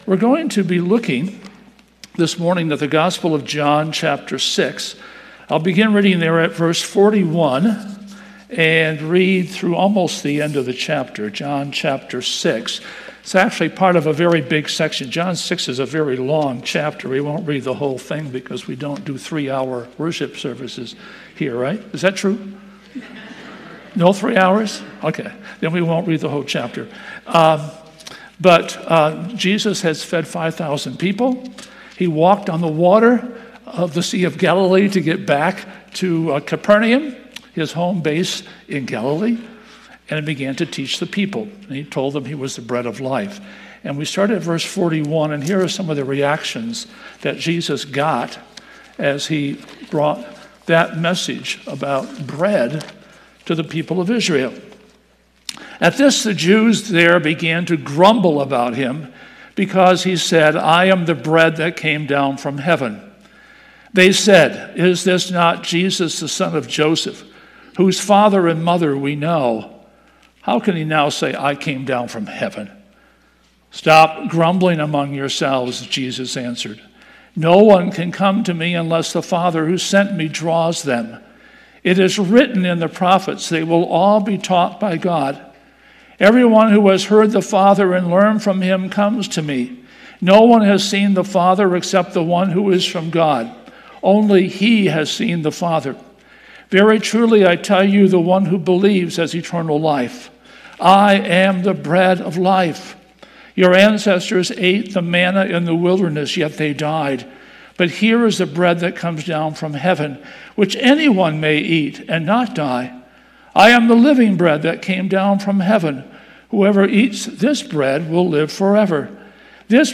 Sermon Recordings | Faith Community Christian Reformed Church
“No Other Savior” August 17 2025 A.M. Service